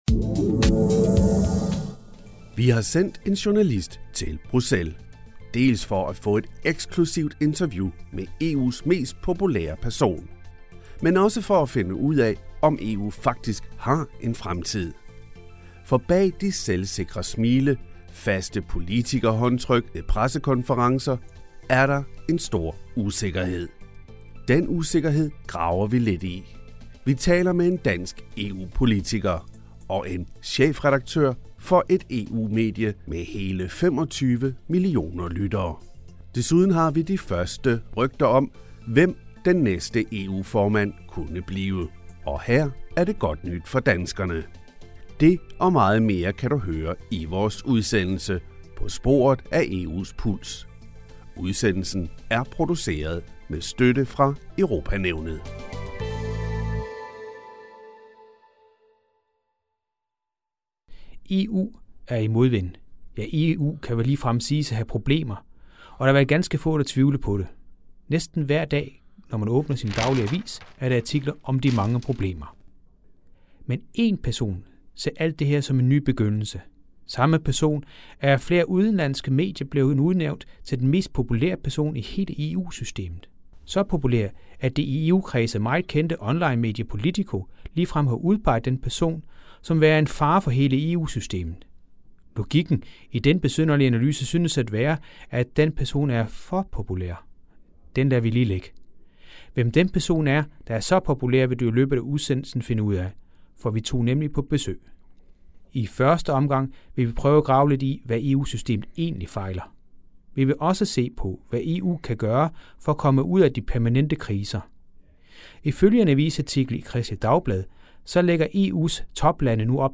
Den danske stat på kollisionskurs: følger ikke EU's naturinitiativ Magasin og interview, 26 min I dag tager vi på landet, hvor den danske natur tilsyneladende er under pres, på trods af EU's forsøg på at bevare og forbedre miljøet.